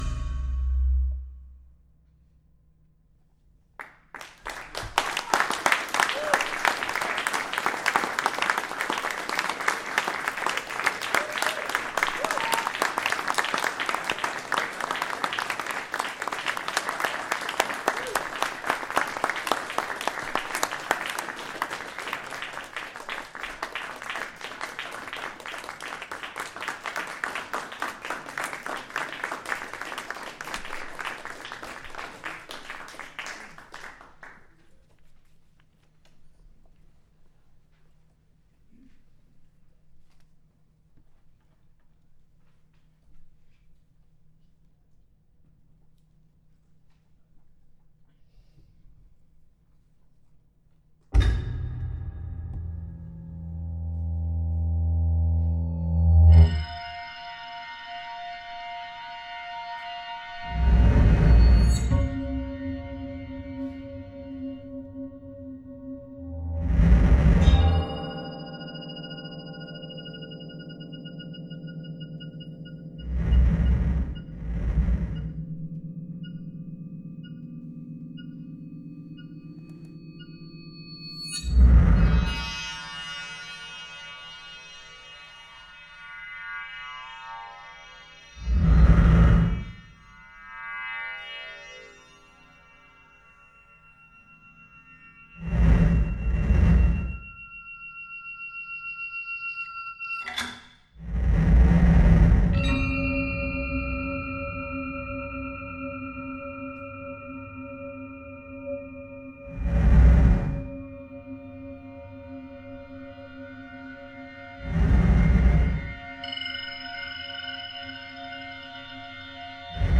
Live from the International Computer Music Conference
Recorded from the Wave Farm Transmit Parter stream.